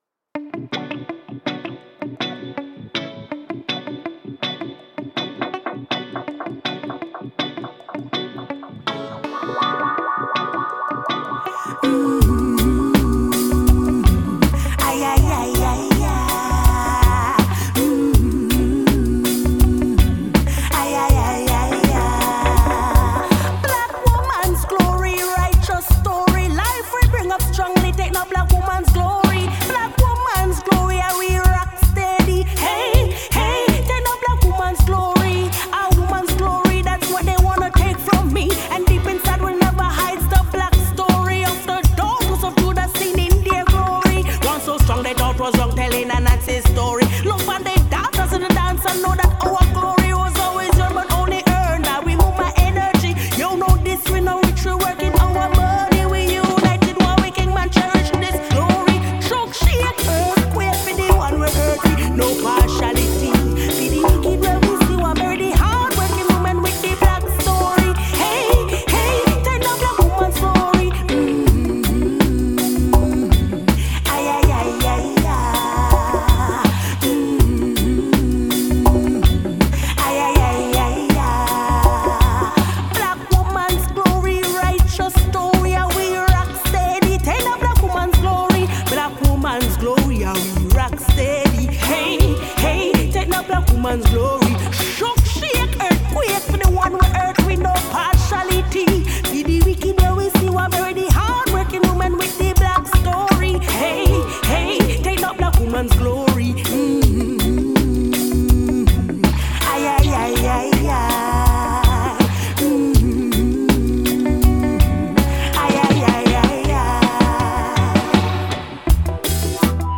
a singer and keyboardist from St. Ann
Genre: Reggae